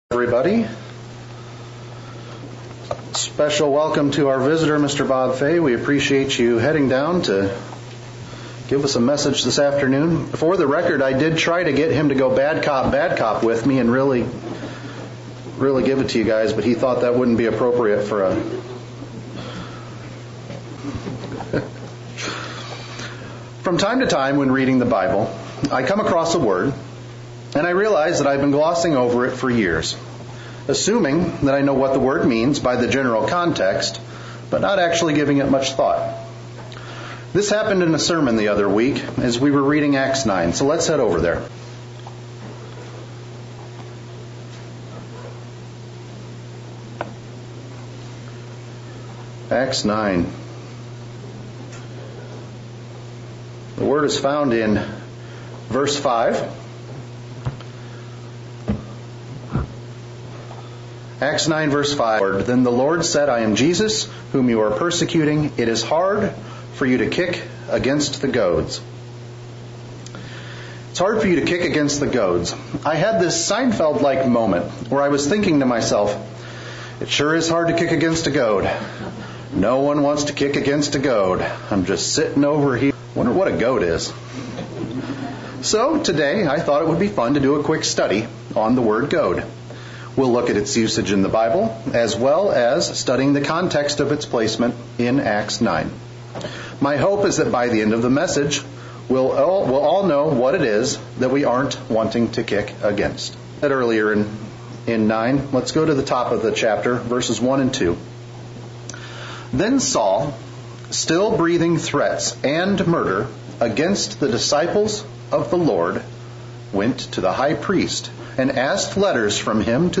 Sermons
Given in Central Illinois